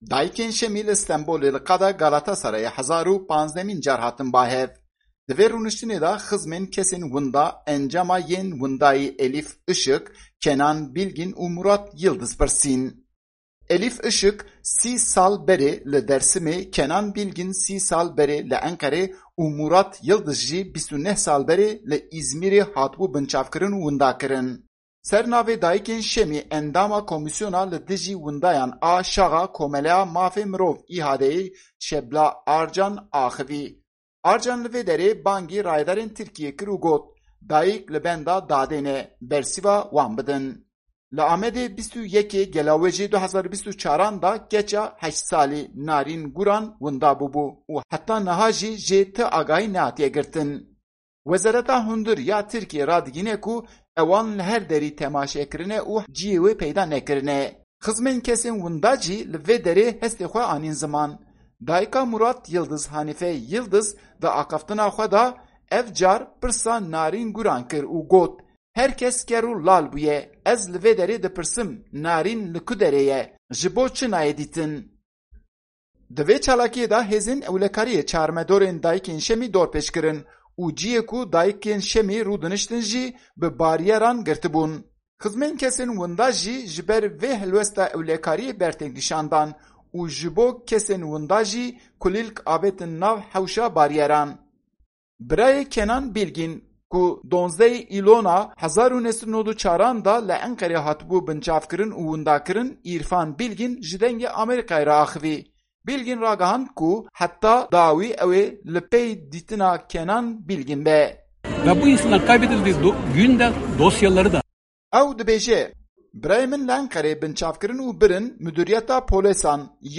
Ji Çalakîya Dayikên Şemî ya cara1015an
Dayikên Şemîy; li Stenbolê li Qada Galatasarayê bo cara 1015an hatin ba hev.